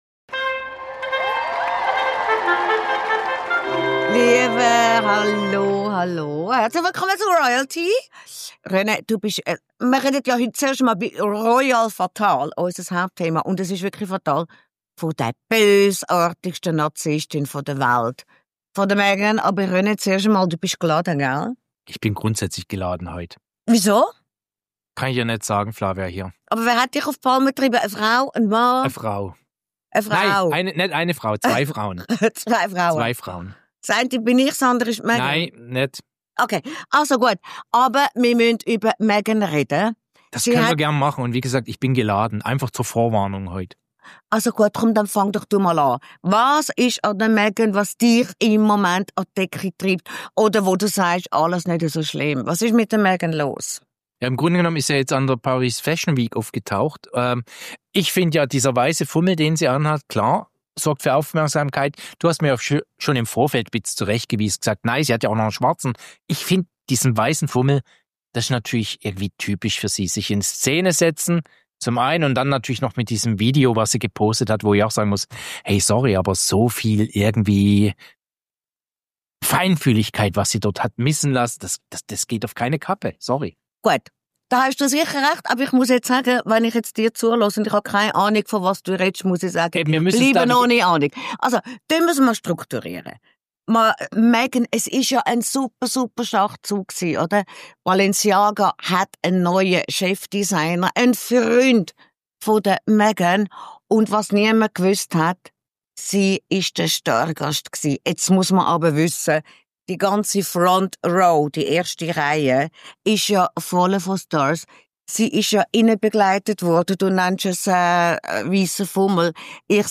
Meghan reagiert auf die Vorwürfe mit einer Rechnung, die so gar nicht aufgeht. Darüber zofft sich unser «RoyalTea»-Expertenduo.